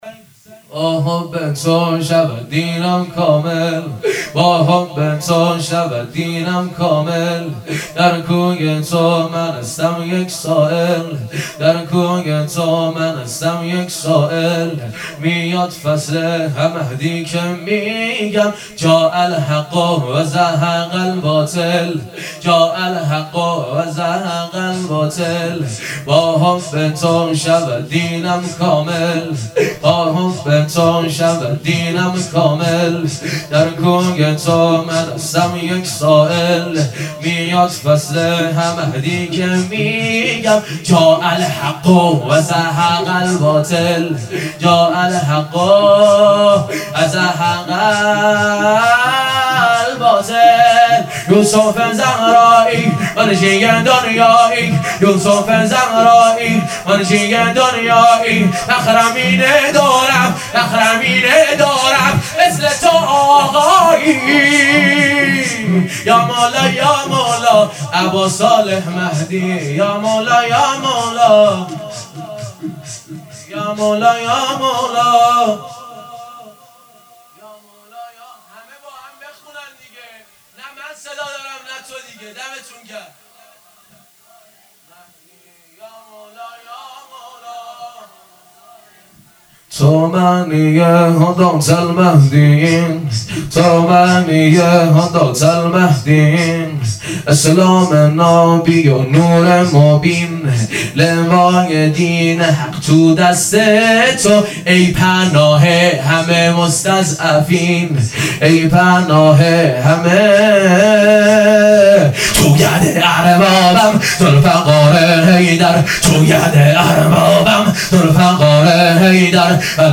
شور
با حب تو شود دینم کامل | شب دوم | مسجد امام موسی بن جعفر علیه السلام | صفر 1440 |هیئت مکتب الرضا علیه السلام | دبیرستان امام سجاد علیه السلام